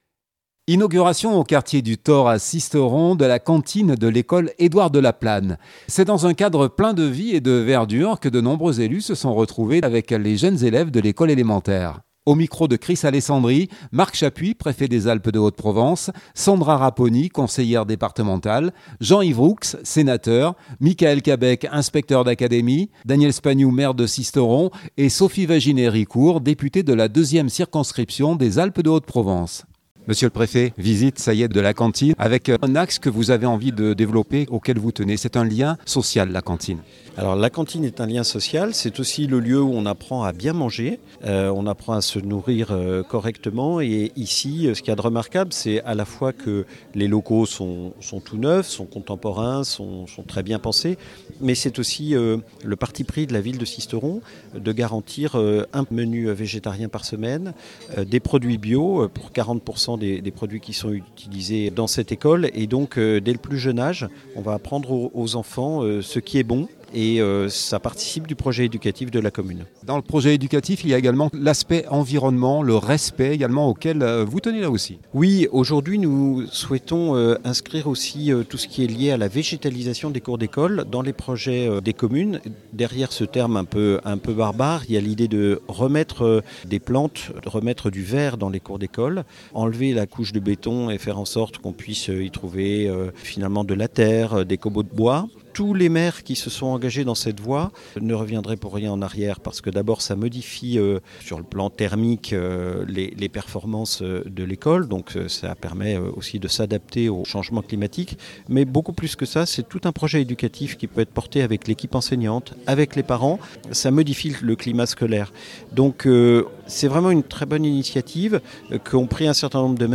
Inauguration au quartier du Thor à Sisteron de la cantine de l’école Edouard Delaplane, c’est dans un cadre plein de vie et de verdure que de nombreux élus se sont retrouvés dans une ambiance pleine de vie avec les jeunes élèves de l’école élémentaire.